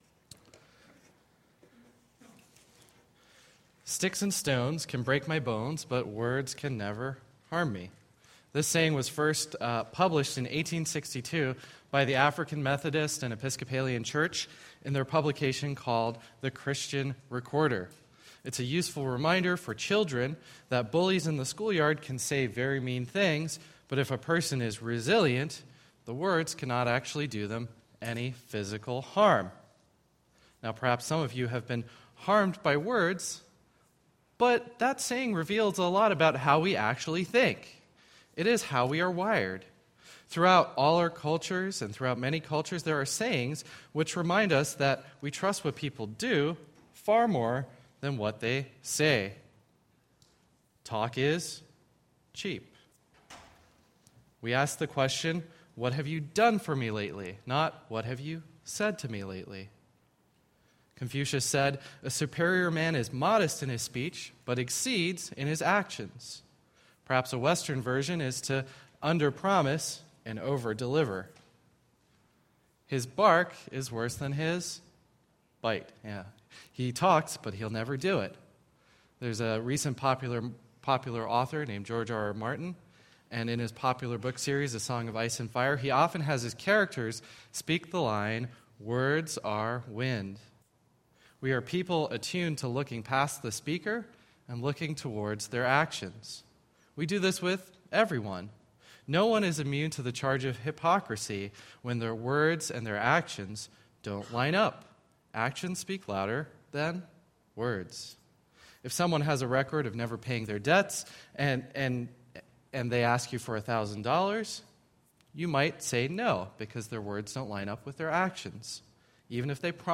A message from the series "Gospel of John."